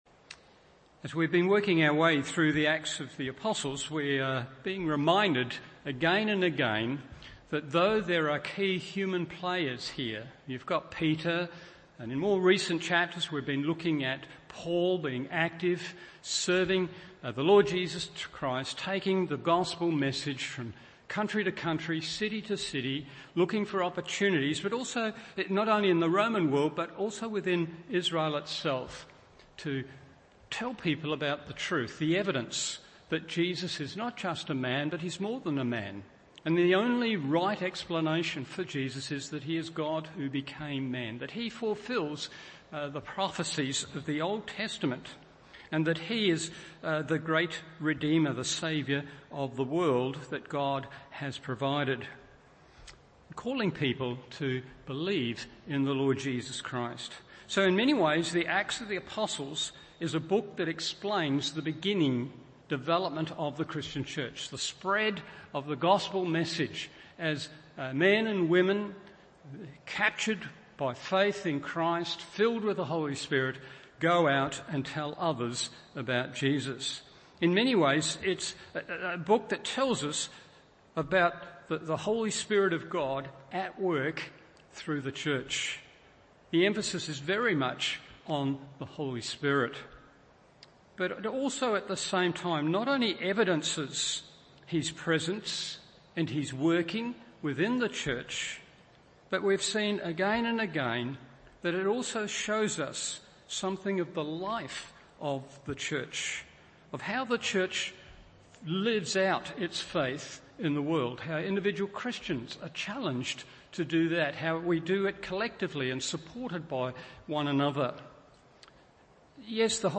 Evening Service Acts 19:18-41 1. In the Believer’s Life 2. In the Believer’s Community…